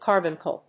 音節car・bun・cle 発音記号・読み方
/kάɚbʌŋkl(米国英語), kάːbʌŋkl(英国英語)/